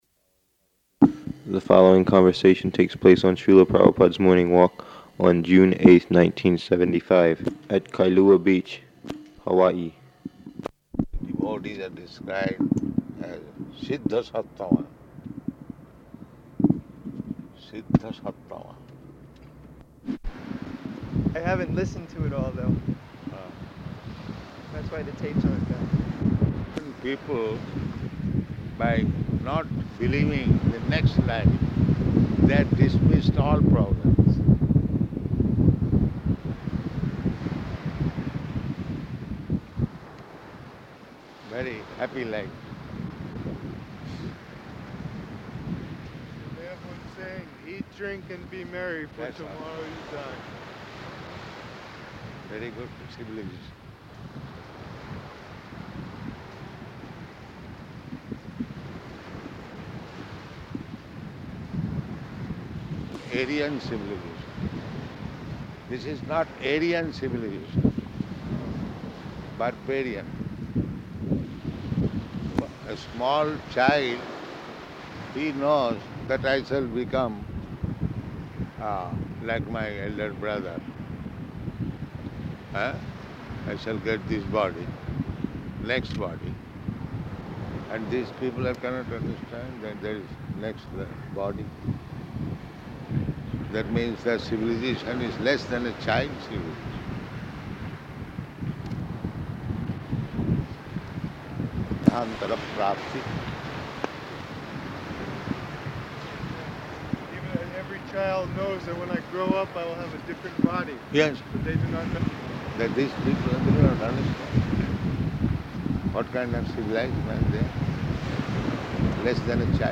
Morning Walk at Kailua Beach
Type: Walk
Location: Honolulu